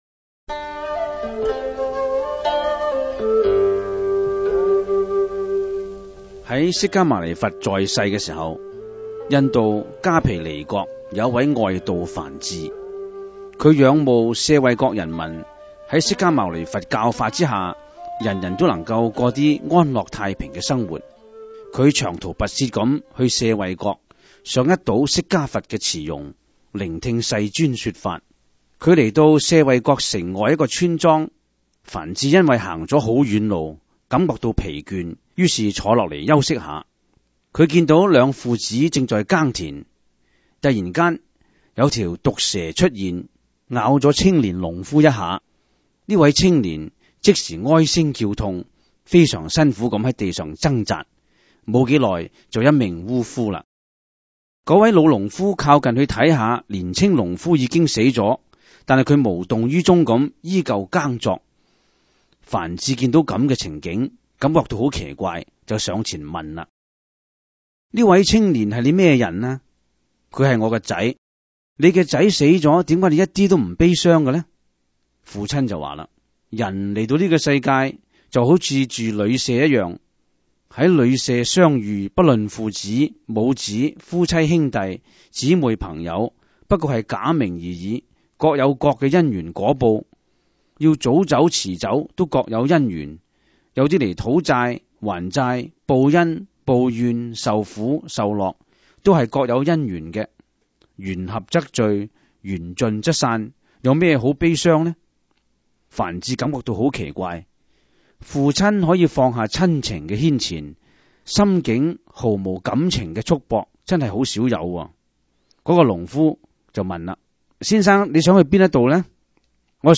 第 十 四 辑          (粤语主讲  MP3 格式)